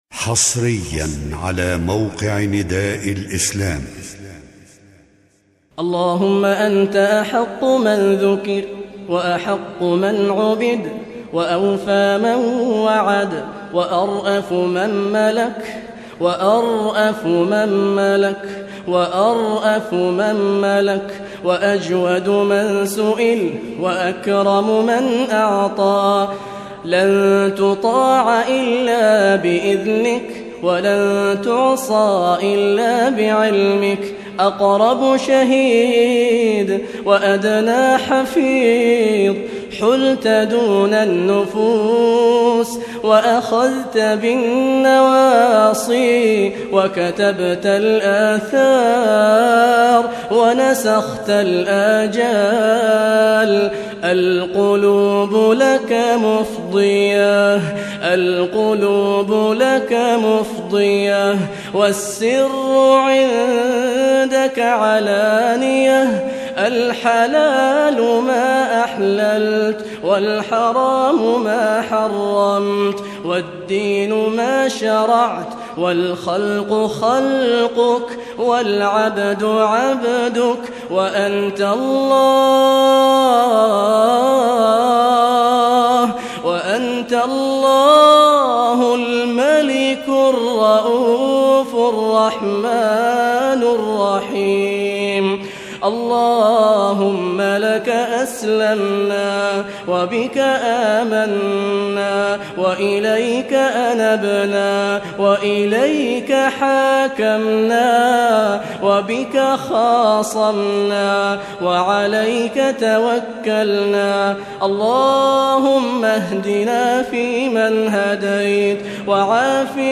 دعاء